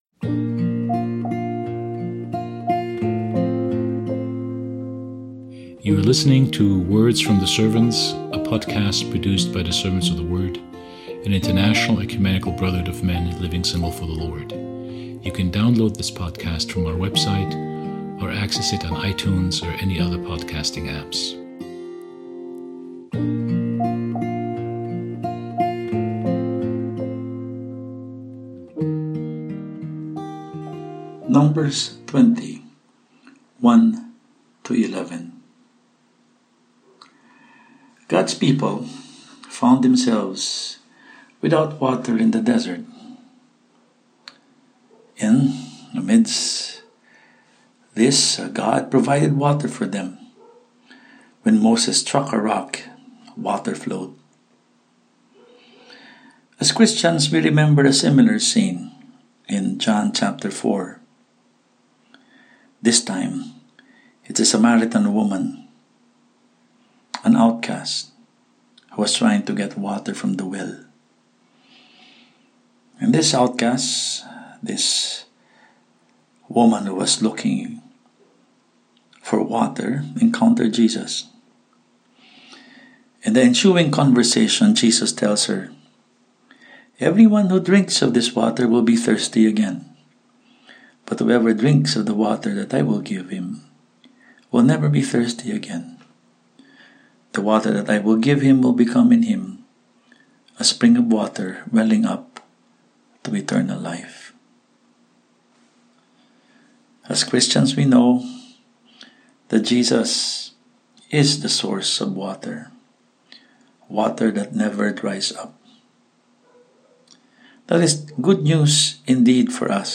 Throughout the 40 Days of Lent, our Words from the Servants podcast will feature brothers from around the world as they give daily commentary on the Pentateuch and the book of Hebrews .